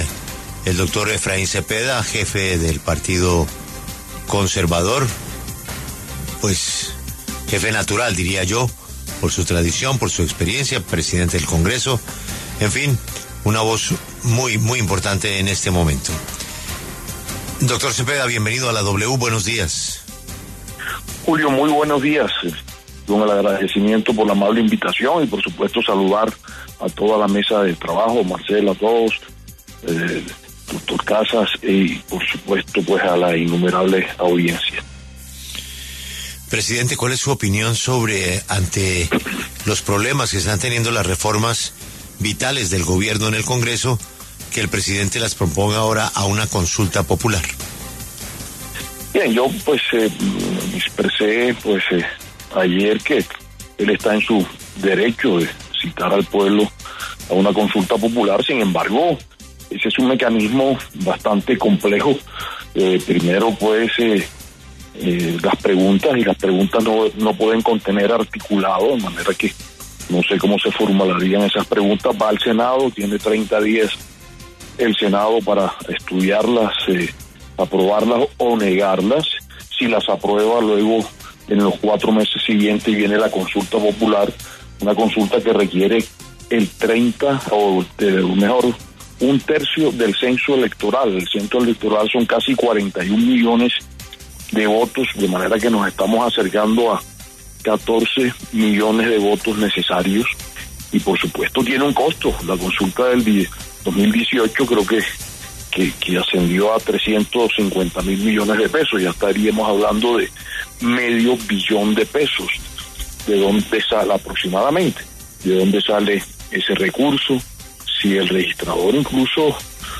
El presidente del Senado, Efraín Cepeda, conversó con La W sobre el anuncio del presidente Petro de enviar a consulta popular las reformas laboral y a la salud.